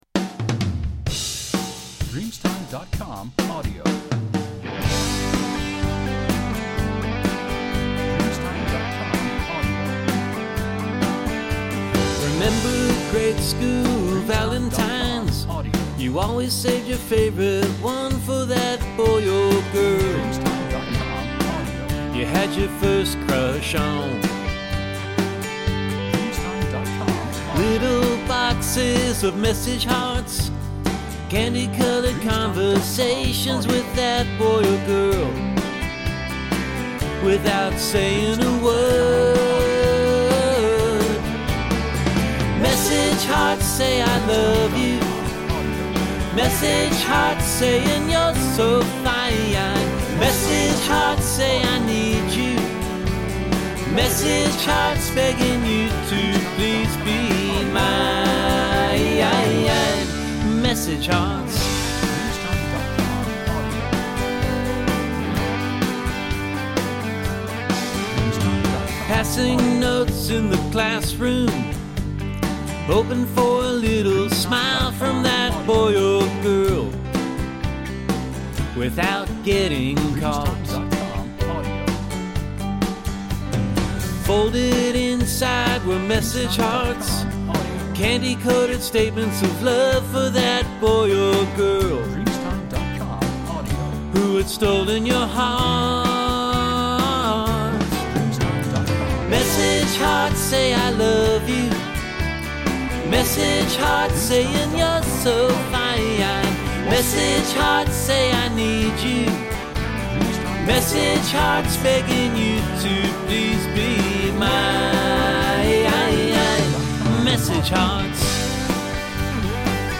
uptempo Americana country pop song